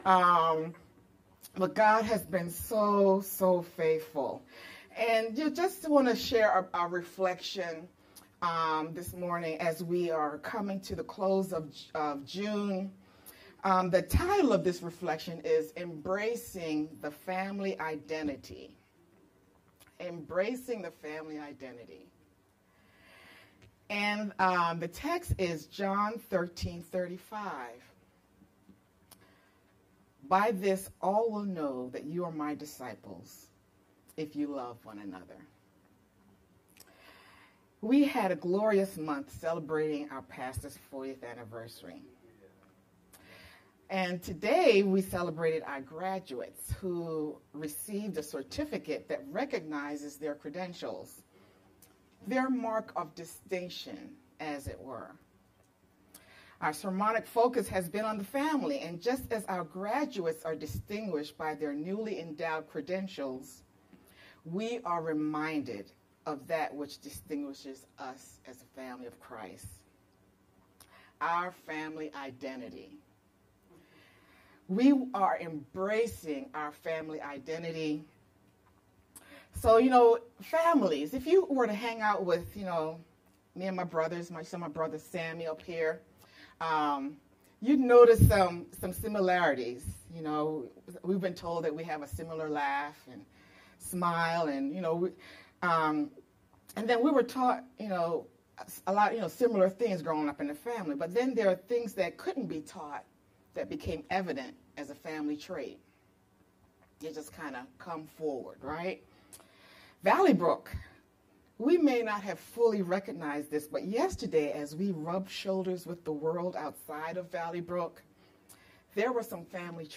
VBCC-Edited-sermon-only-June-30th_Converted.mp3